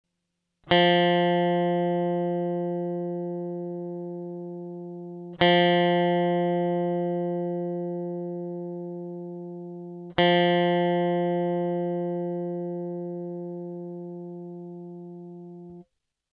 Drop C Guitar Tuning
Tune down the open low E string until it matches the C note fretted on the A string.